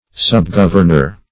Subgovernor \Sub*gov"ern*or\, n. A subordinate or assistant governor.